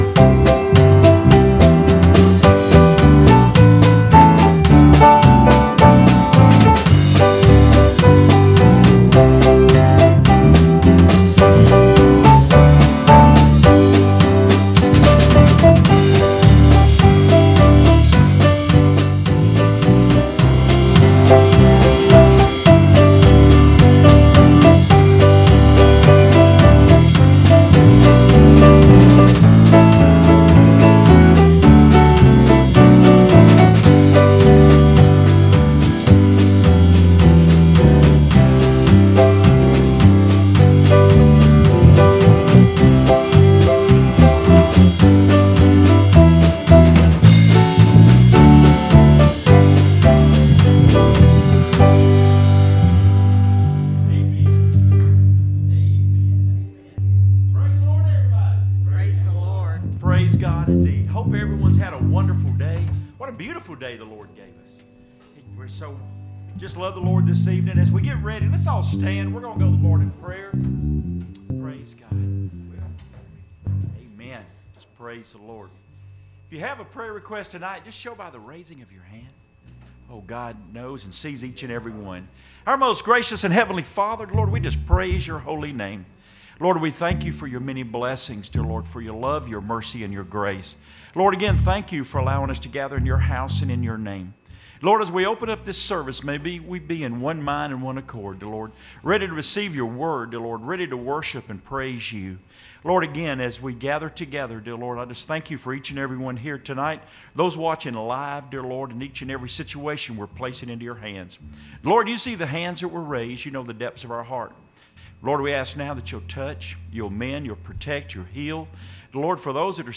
Sunday Night Worship